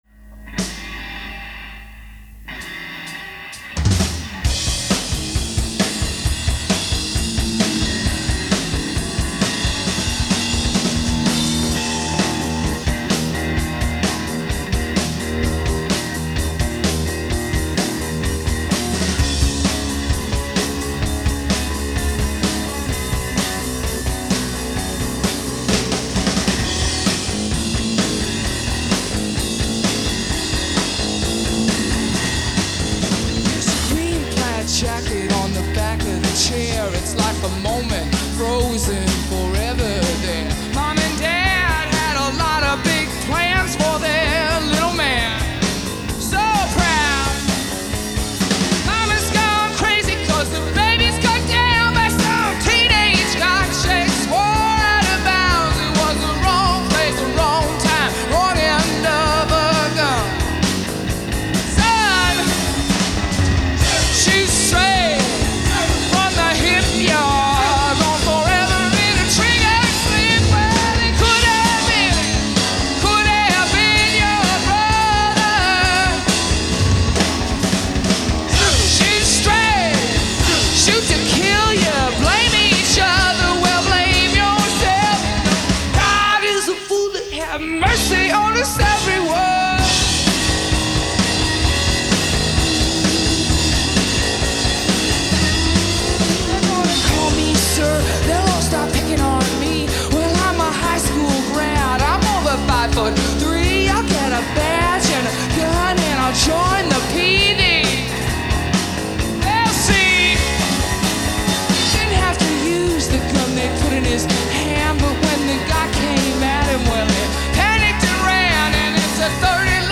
recorded at the Central Club, Richmond
Band Soundboard